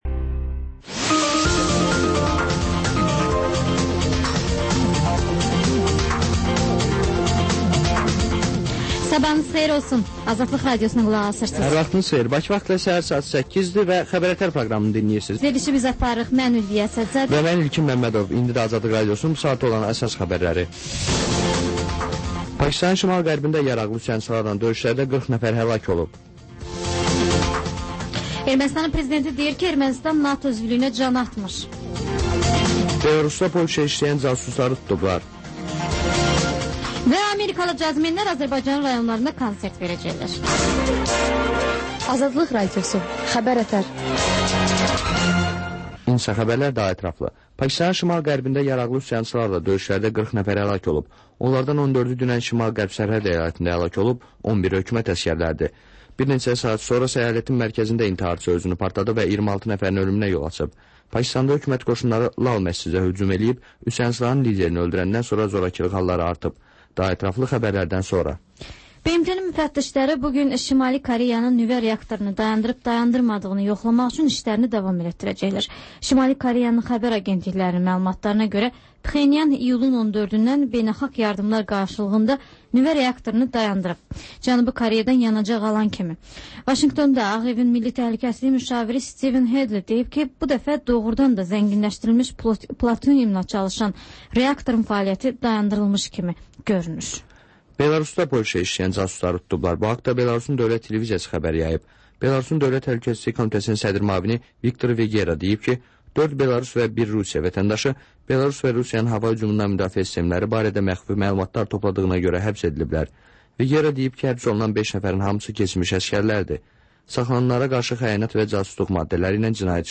Xəbərlər, müsahibələr.